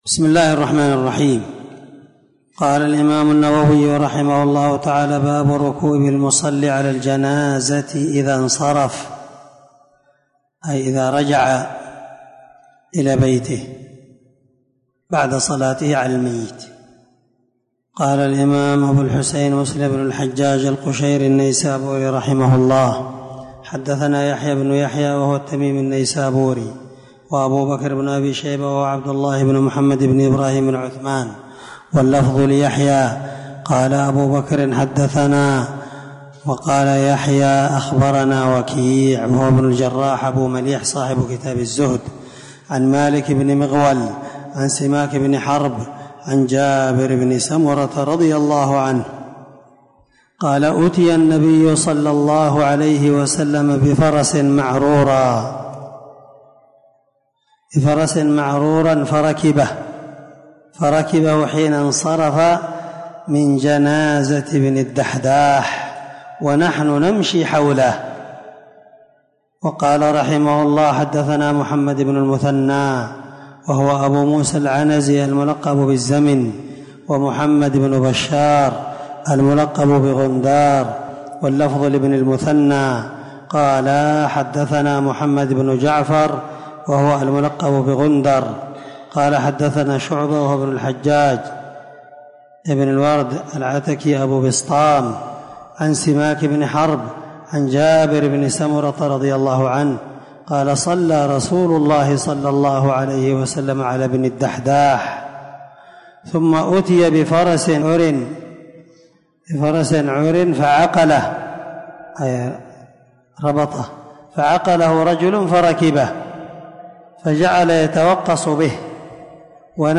• سلسلة_الدروس_العلمية
• ✒ دار الحديث- المَحاوِلة- الصبيحة.